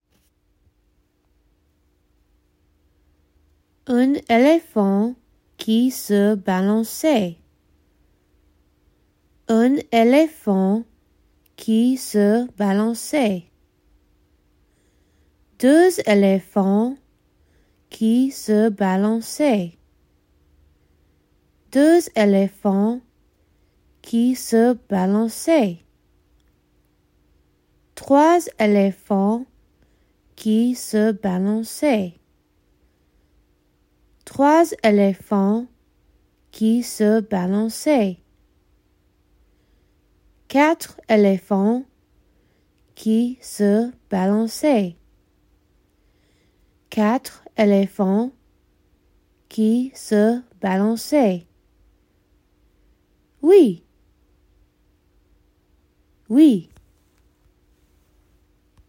x325UnElephantPronunciationGuide.mp3